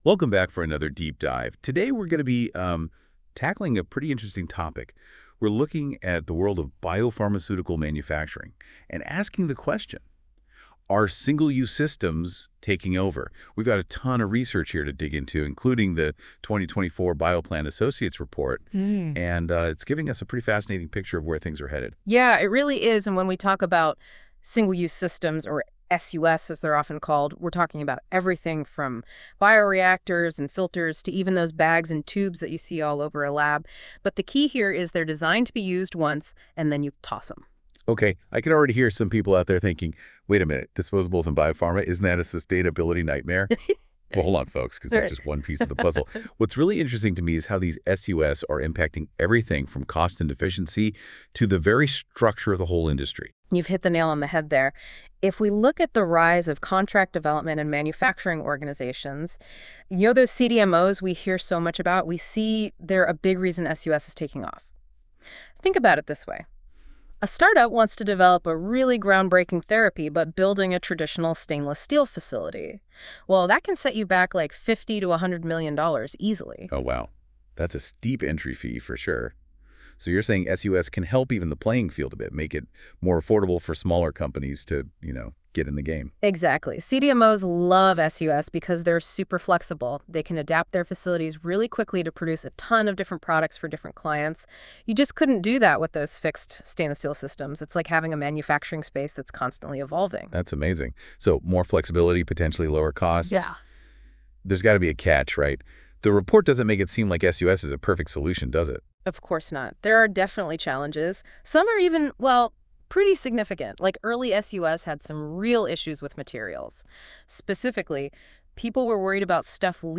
Single-Use Biopharma Manufacturing Discussion [11:00 Notebook AI]